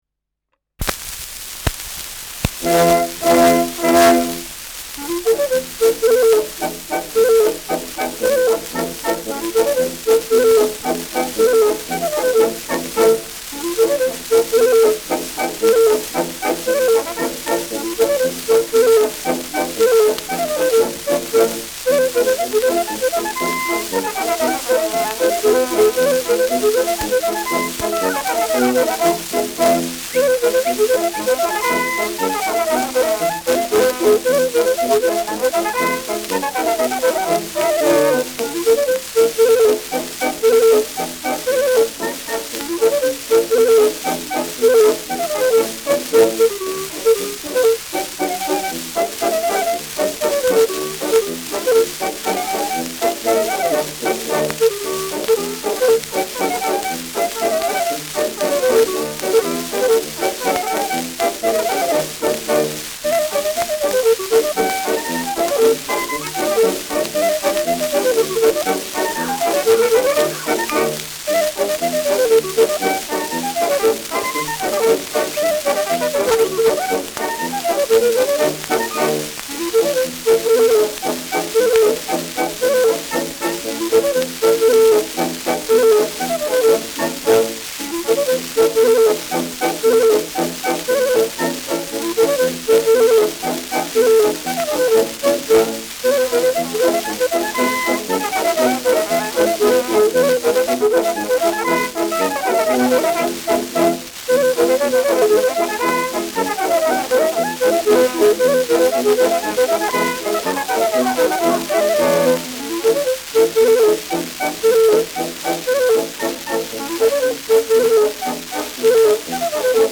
Der Seppl von Gaging : Klarinettenschottisch
Schellackplatte
präsentes Rauschen : Knacken zu Beginn : leichtes Nadelgeräusch : vereinzeltes Knistern
Militärmusik des k.b. 14. Infanterie-Regiments, Nürnberg (Interpretation)
[Nürnberg] (Aufnahmeort)